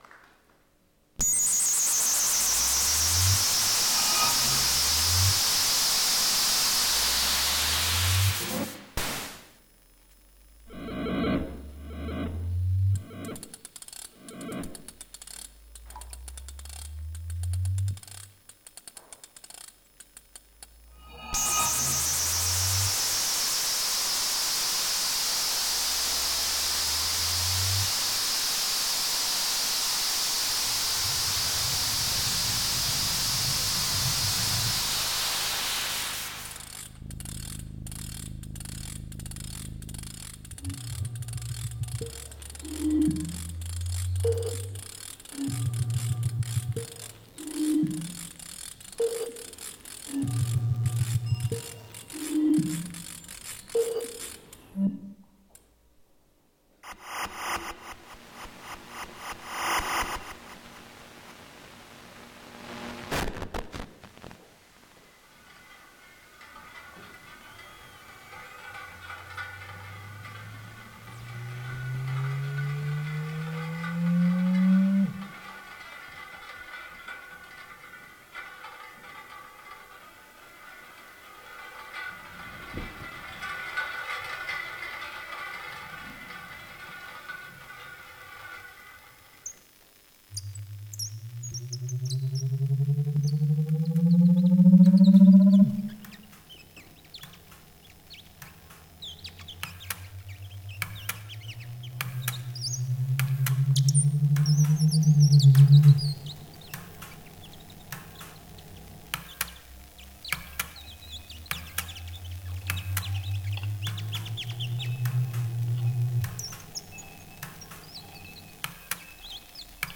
Arika_INSTAL_10_Resonance_Radio_Overheard_Performance.m4a